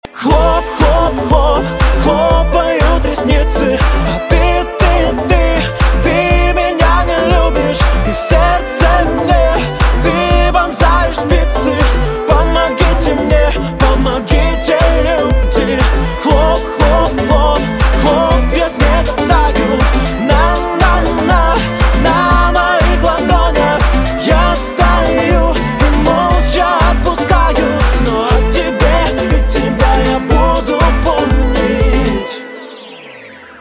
поп
мужской вокал
грустные
озорные